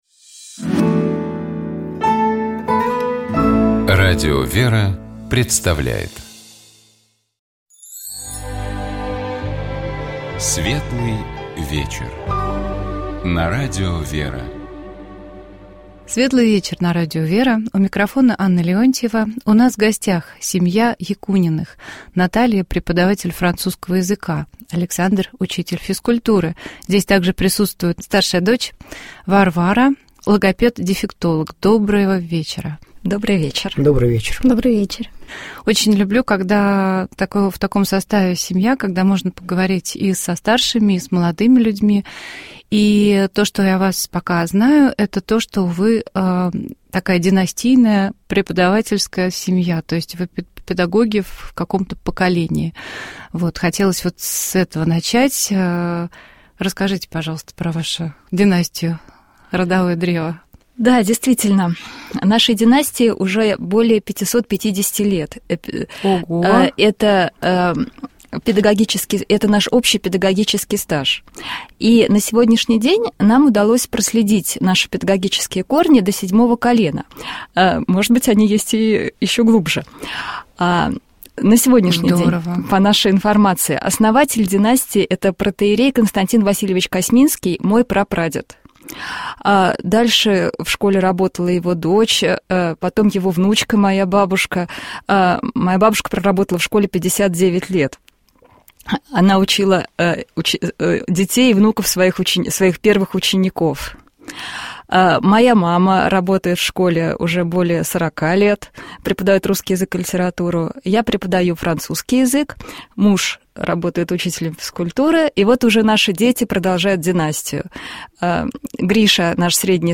Давайте поразмышляем над текстом этого песнопения, и послушаем его отдельными фрагментами в исполнении сестёр храма Табынской иконы Божией Матери Орской епархии. Первая часть «Символа веры» начинается с утверждения о том, что Господь сотворил весь видимый и невидимый нами мир.